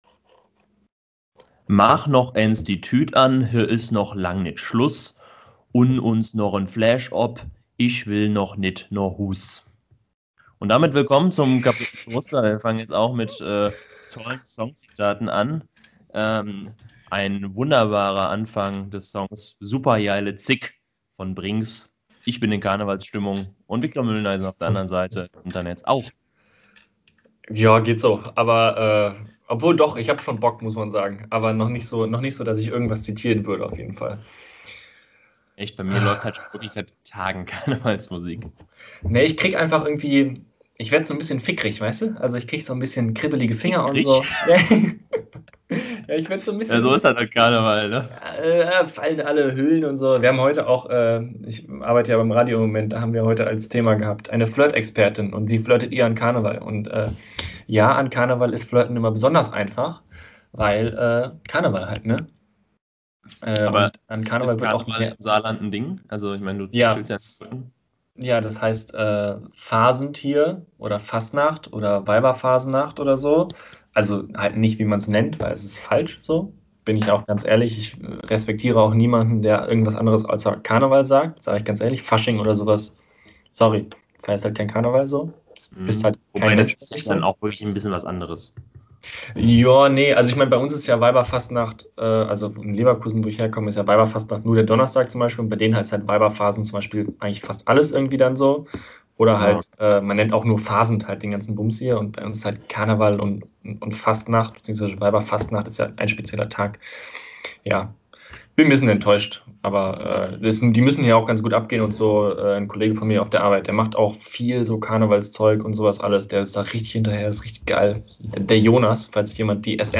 In den drei liebsten Vier geht es in dieser Woche um die geilsten Seriencharaktere. Die Aufnahme wird von einem Hund gestört
Serie: Comedy